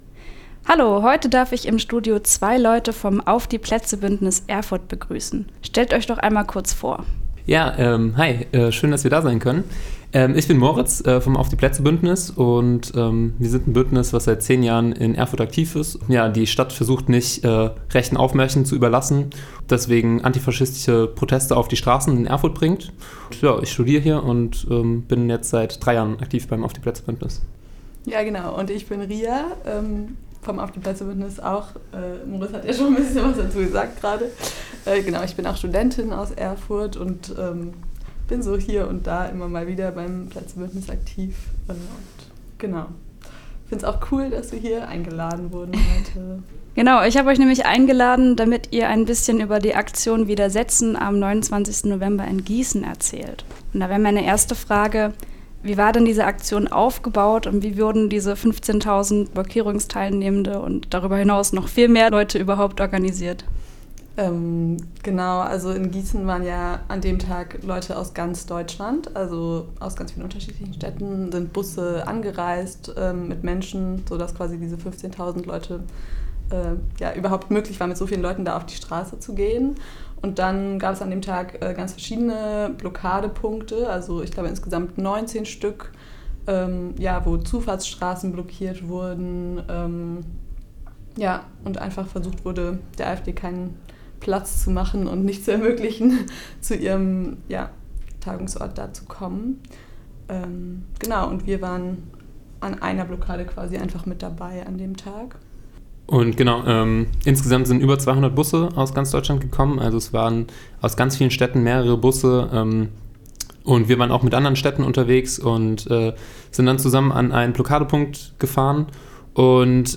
Yoga in der Sitzblockade - Gespräch